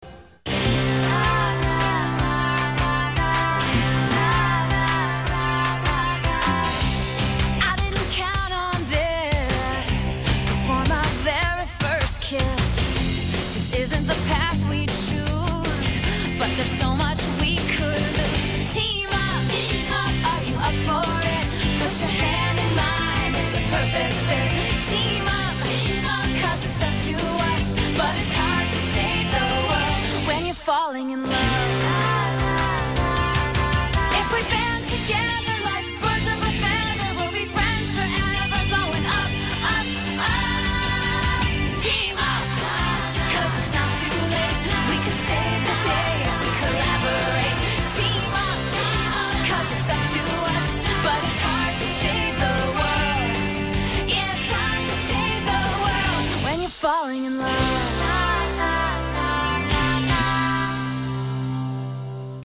Sigla Americana